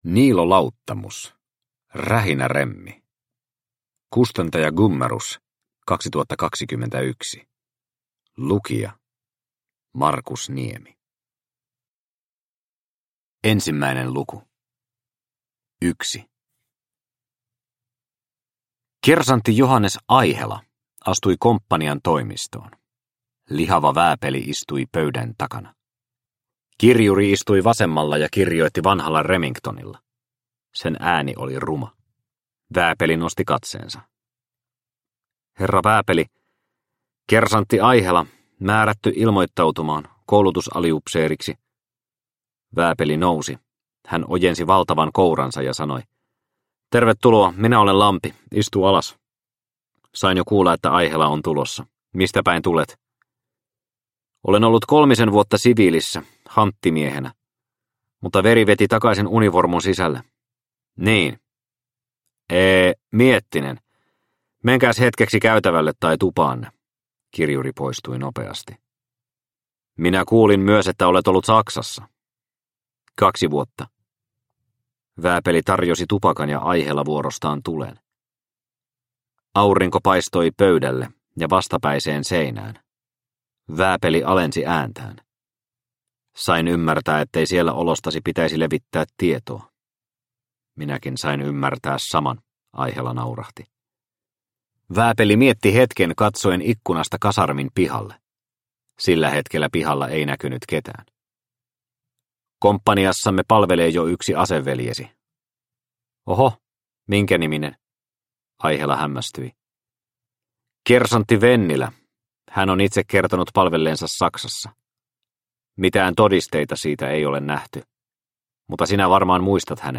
Rähinäremmi – Ljudbok – Laddas ner